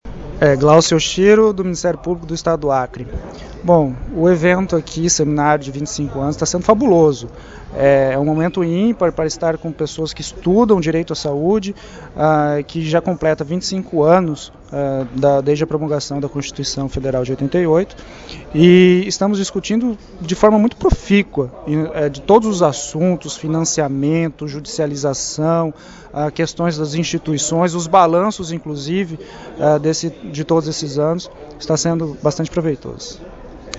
Ouça as opiniões de participantes e palestrantes sobre o Seminário 25 Anos do Direito à Saúde: Integralidade, Responsabilidade e Interdisciplinaridade – Afinal, do que se trata?.